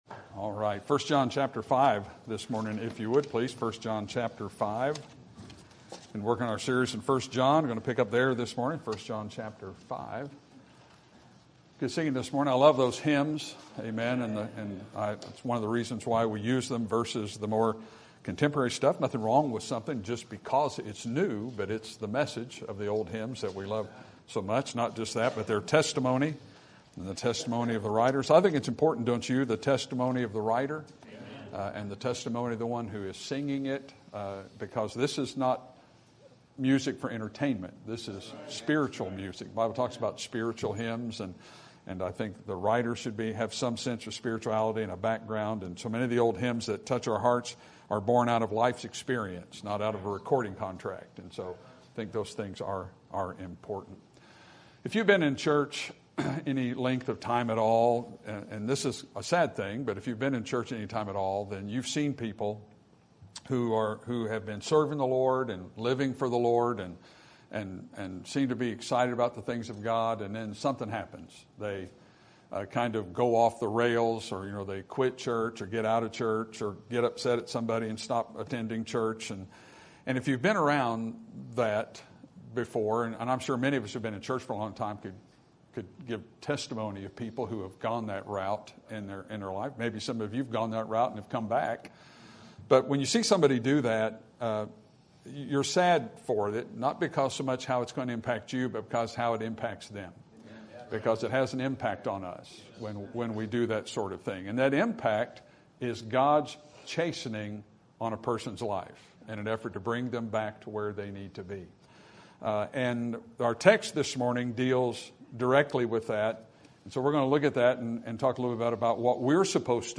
Sermon Date